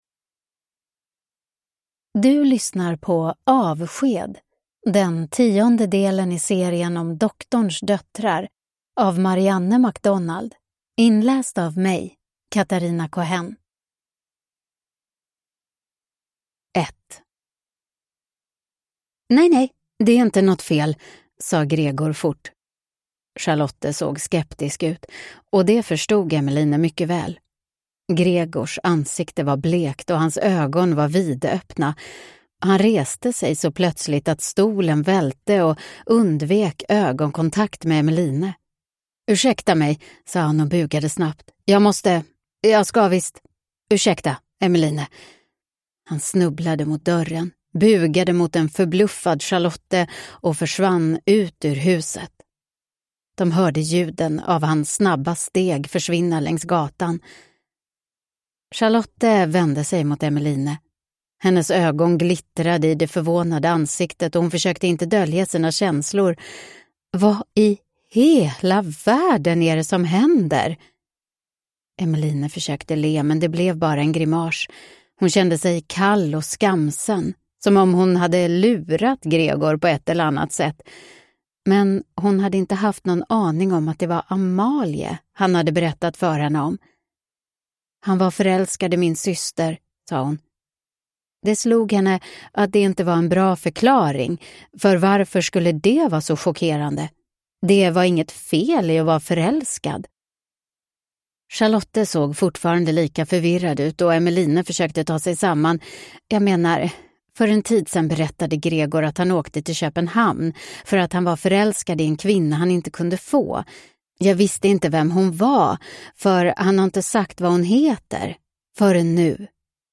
Avsked – Ljudbok